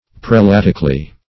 Search Result for " prelatically" : The Collaborative International Dictionary of English v.0.48: Prelatically \Pre*lat"ic*al*ly\, adv.